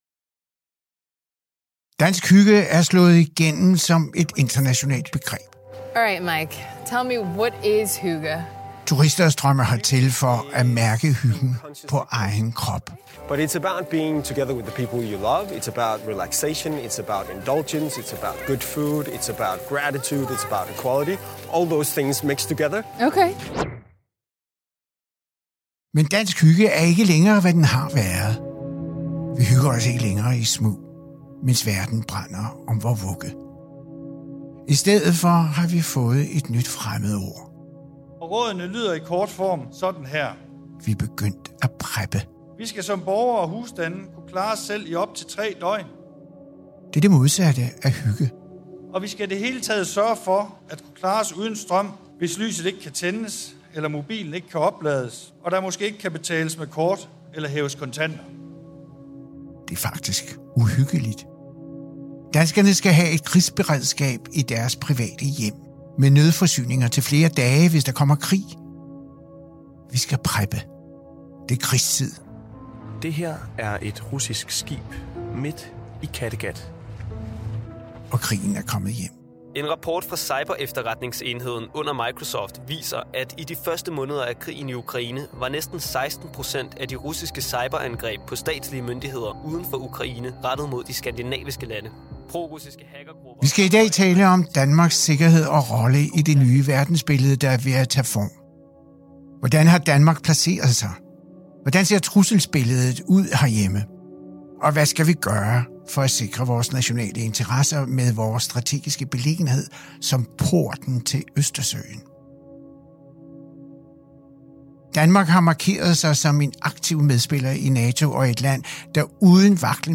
Og hvilken sikkerhedspolitisk fremtid står Danmark og resten af Vesten overfor? Til at svare på de spørgsmål har Samuel Rachlin fået tidligere FE-chef Lars Findsen i studiet.